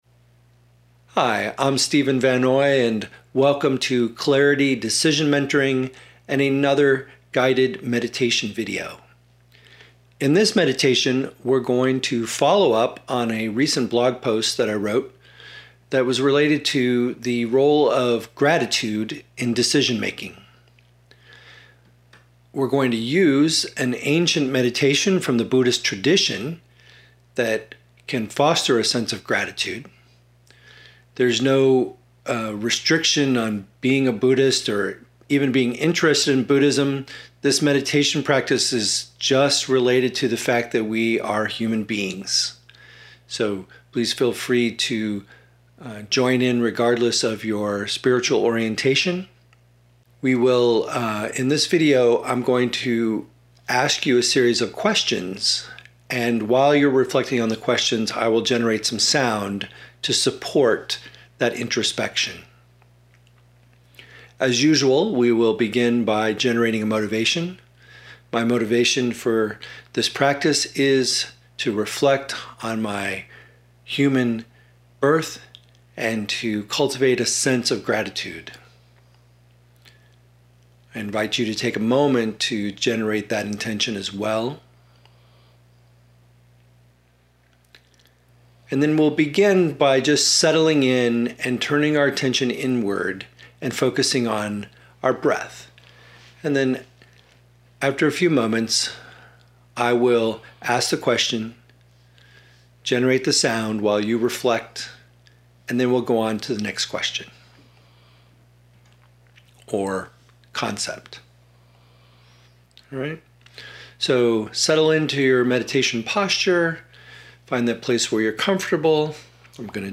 Guided Meditation for Gratitude
Guiding with crystal bowls I’ve just posted a new guided meditation in video and audio format.